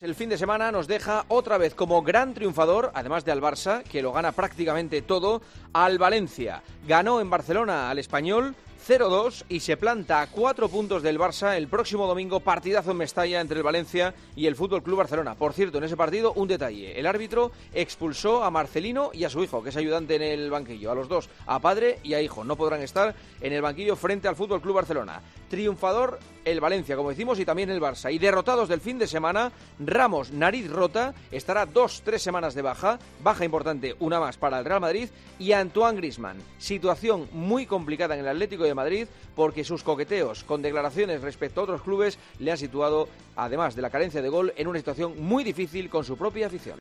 El comentario de Juanma Castaño
El Barça afrontará en Valencia un duelo directo por La Liga, en el comentario de Juanma Castaño en 'Herrera en COPE'